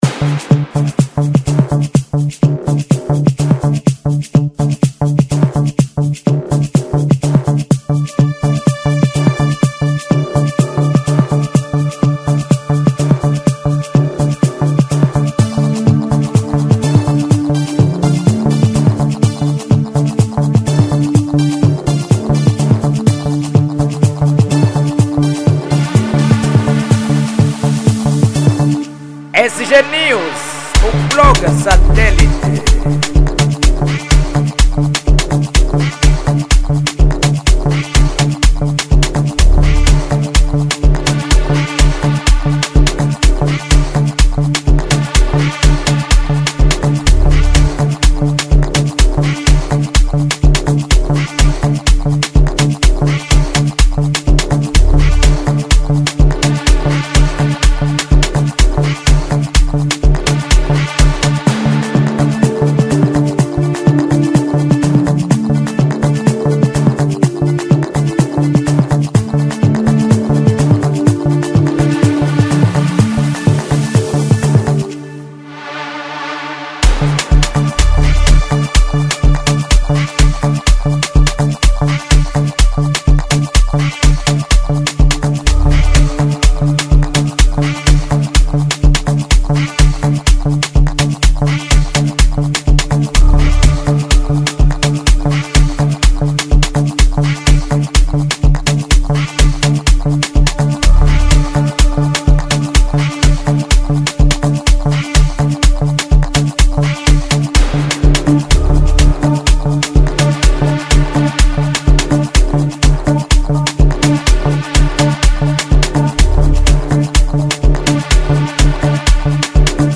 Género : Afro House